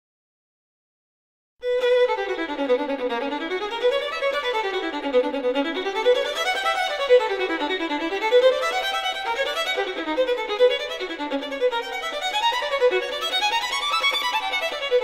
Je hoort 5 korte fragmenten van verschillende instrumenten.